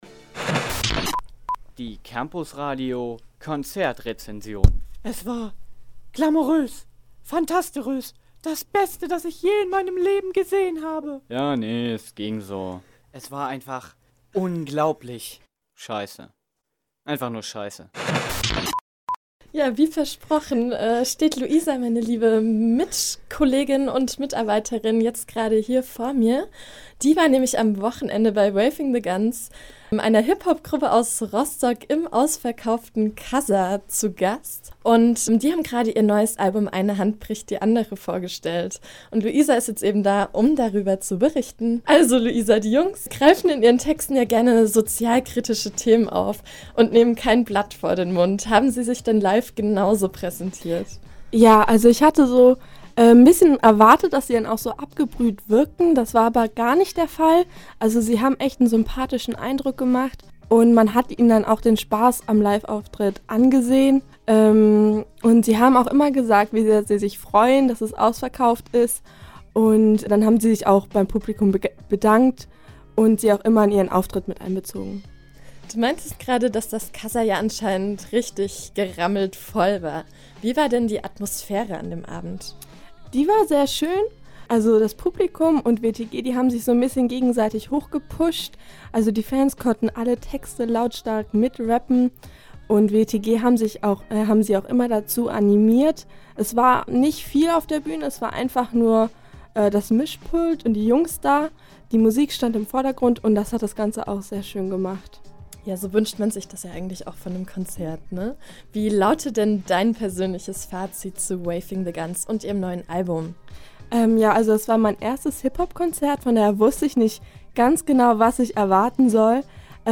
Konzertrezension: Waving The Guns